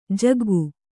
♪ jaggu